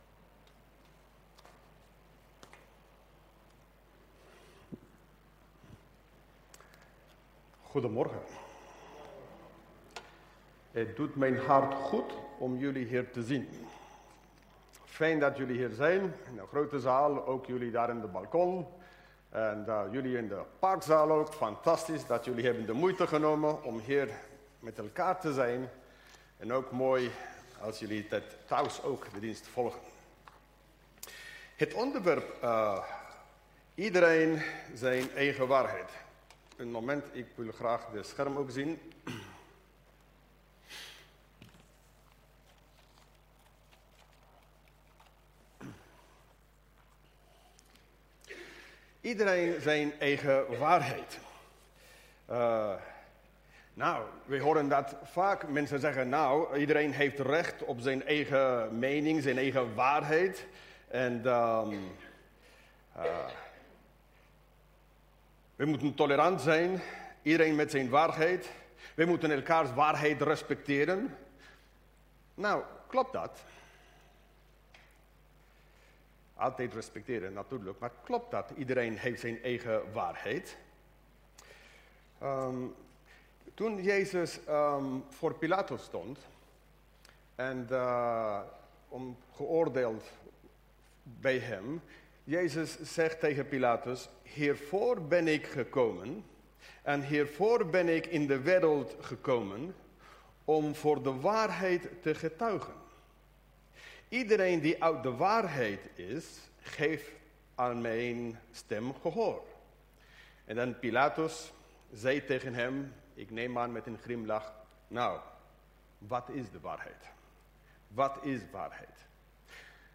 Toespraak 7 november: Iedereen zijn eigen waarheid? - De Bron Eindhoven